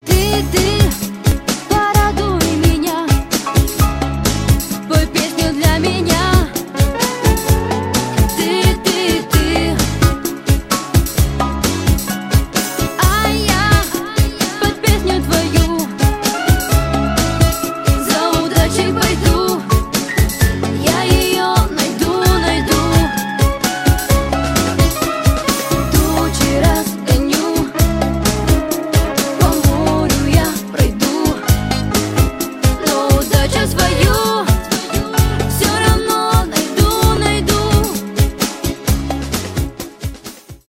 ретро , поп